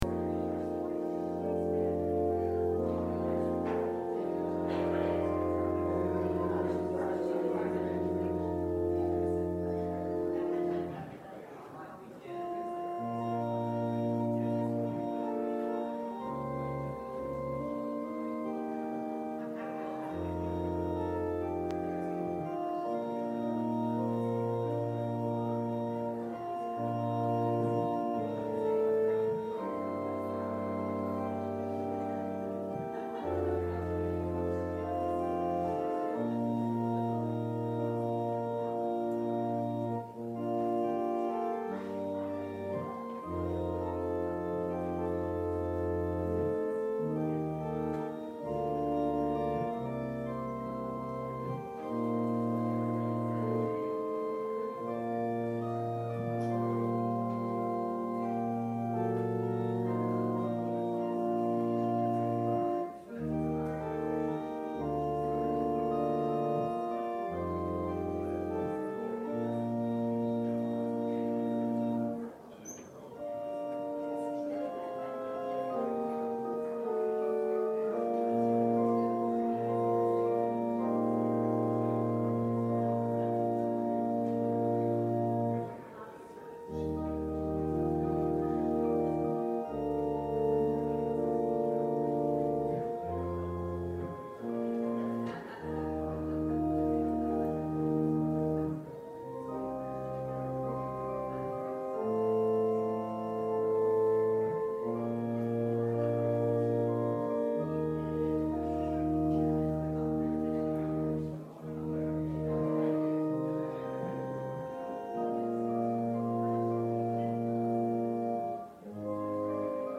James 3:1-12 Service Type: Sunday Worship The Great Toilet Paper Shortage of 1973!